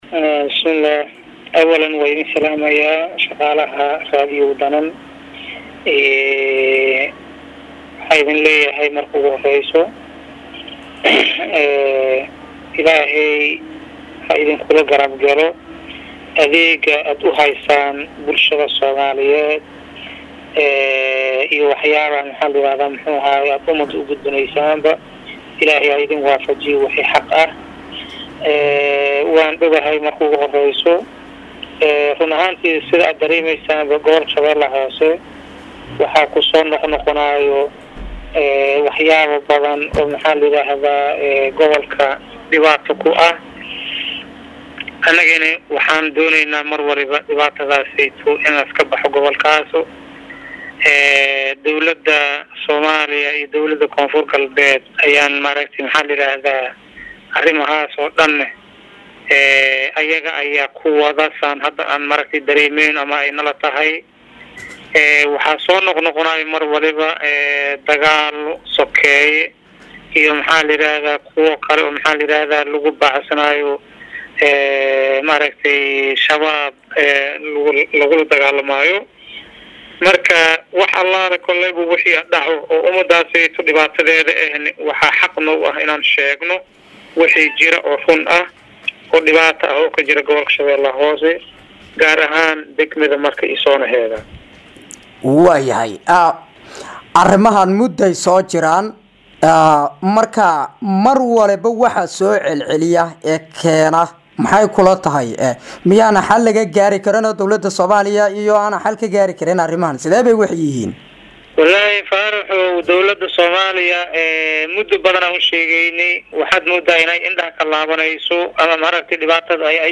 Marka(INO)- Taliyaha Ciidamada Daraawiishta Dowlada Koonfur Galbeed Soomaaliya C/laahi Cali Axmed Waafow oo wareysi siyey Waraahinta ayaa ka hadlay arimo badan oo ka taagan Marka Ee Xarunta Gobolka Shabellaha Hoose.